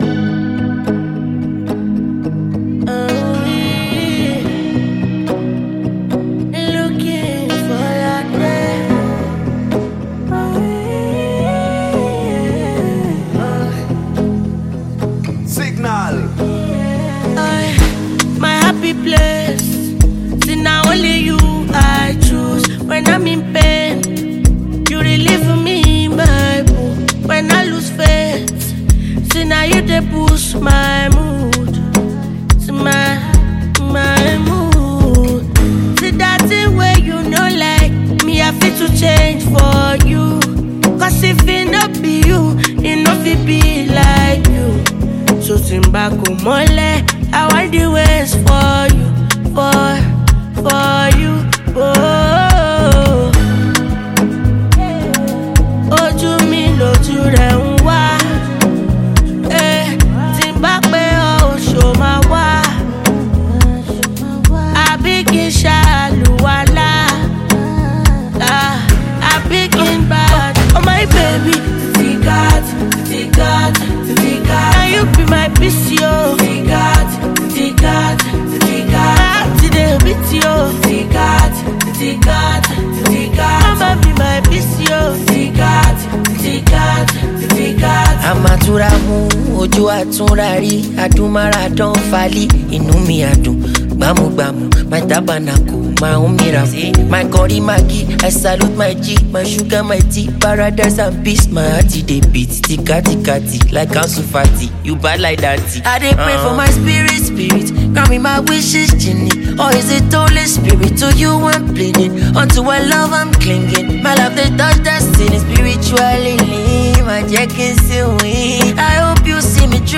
Afrobeats
With nice vocals and high instrumental equipments